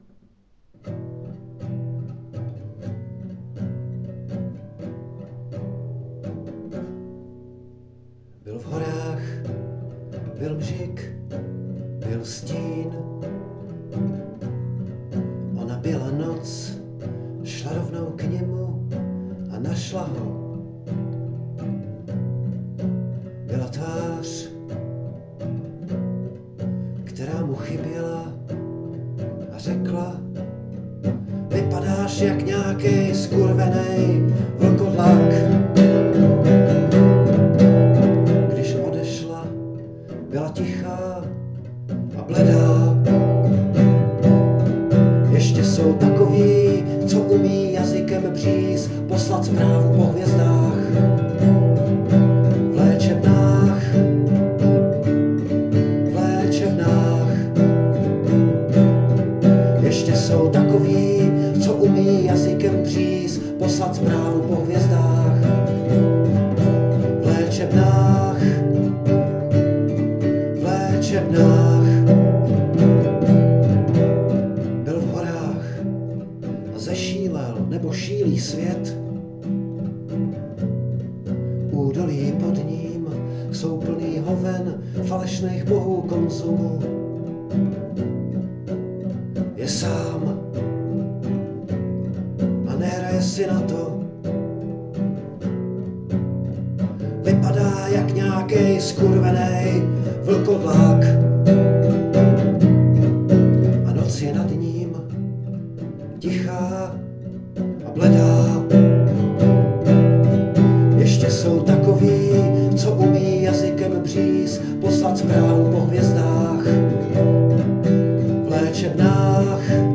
Trošku mi to atmosférou a harmonií připomnělo Kryla, tedy až na ten omezený počet akordů, absencí nějaké výraznější melodie a hlubšího smyslu textu (kromě toho, že nechce... rozmlouvat s idioty, protože je... lepší než oni:-))